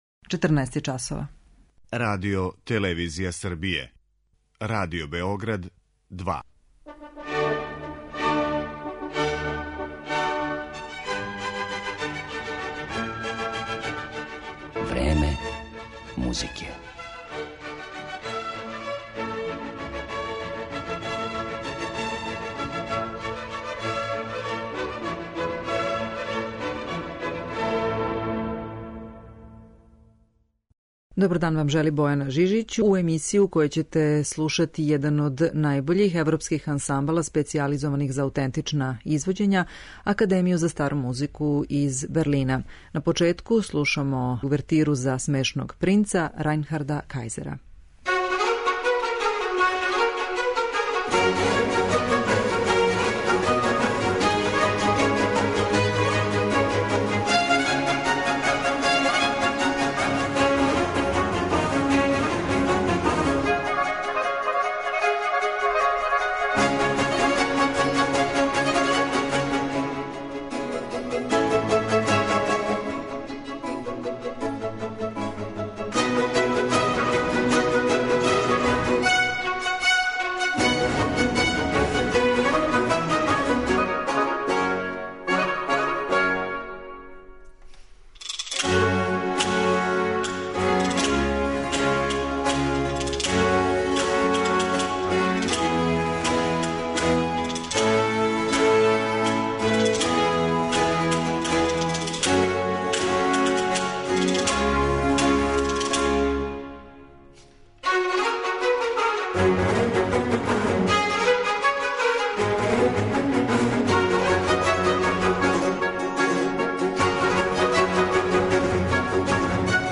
свирао на аутентичним инструментима
барокну и ранокласичну музику